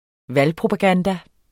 Udtale [ ˈval- ]